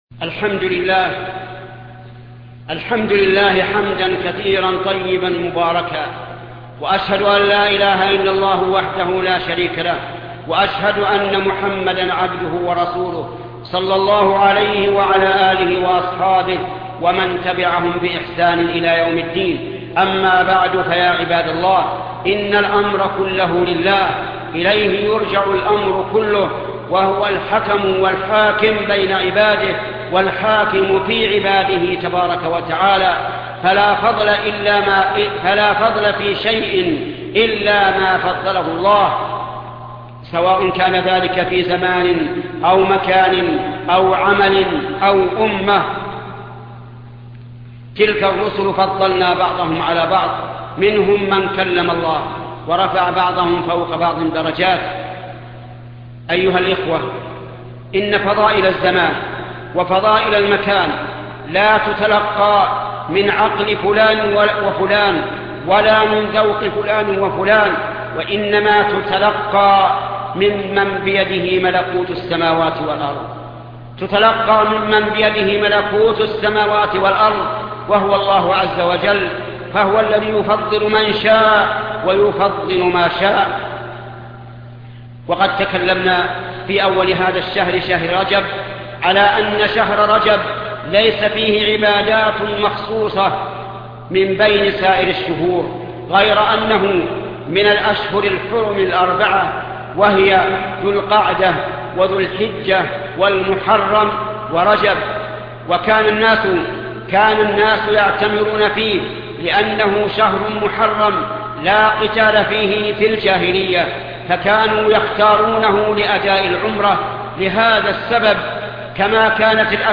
خطبة وجوب الحجاب على المرأة ـ آداب حول دخول المسجد الشيخ محمد بن صالح العثيمين